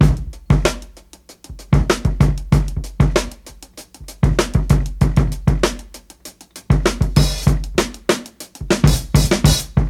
• 97 Bpm Drum Groove G Key.wav
Free breakbeat sample - kick tuned to the G note. Loudest frequency: 1002Hz
97-bpm-drum-groove-g-key-9rX.wav